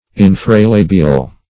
Search Result for " infralabial" : The Collaborative International Dictionary of English v.0.48: Infralabial \In`fra*la"bi*al\, a. (Zool.) Below the lower lip; -- said of certain scales of reptiles and fishes.